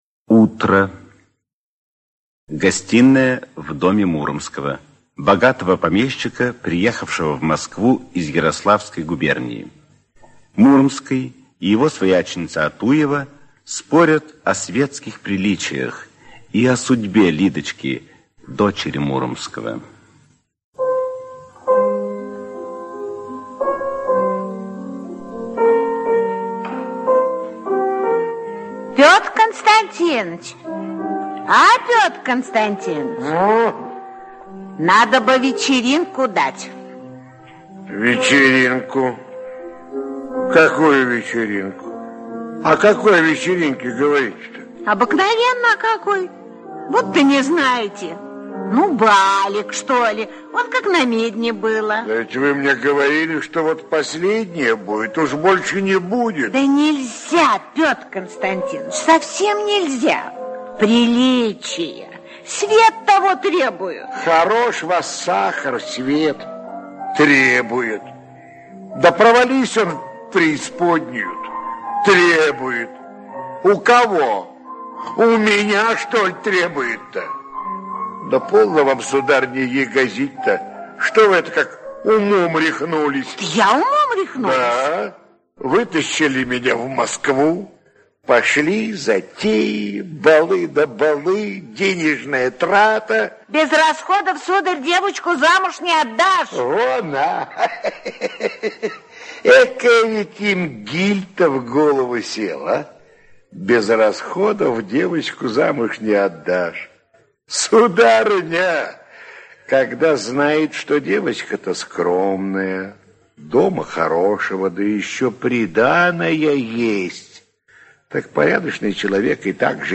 Aудиокнига Свадьба Кречинского (спектакль) Автор Александр Сухово-Кобылин Читает аудиокнигу Актерский коллектив.